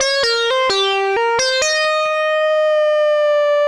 Index of /musicradar/80s-heat-samples/130bpm
AM_CopMono_130-C.wav